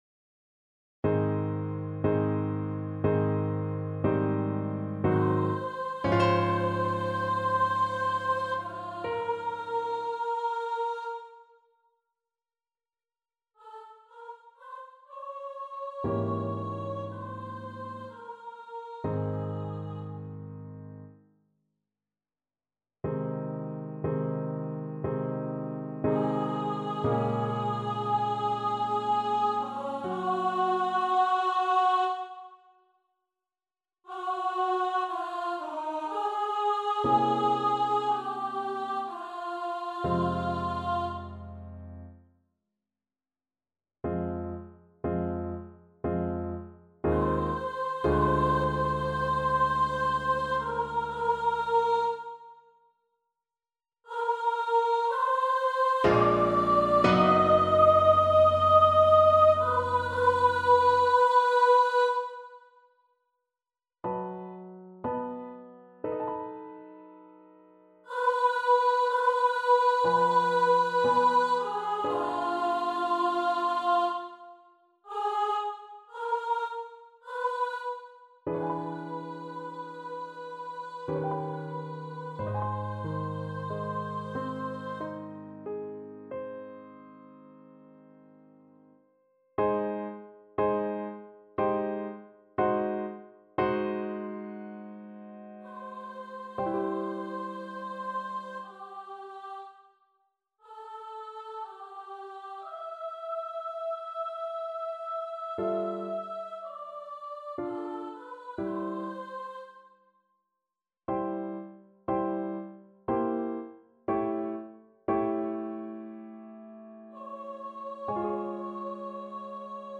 3/4 (View more 3/4 Music)
~ = 60 Langsam, leidenschaftlich
Classical (View more Classical Voice Music)